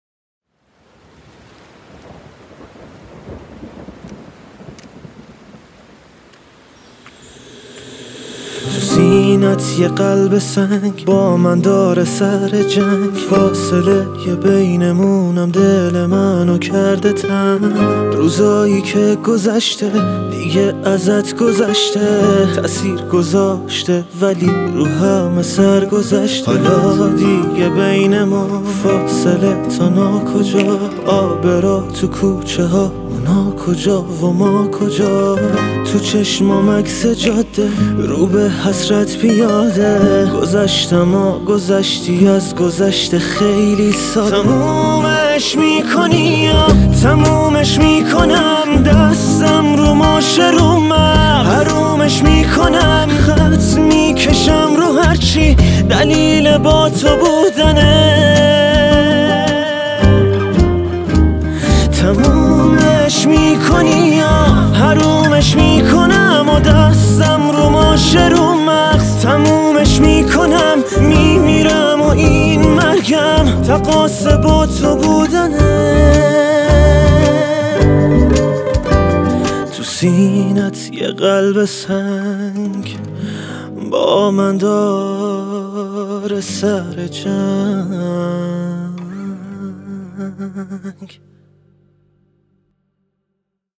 آهنگهای پاپ فارسی
موزیک بی کلام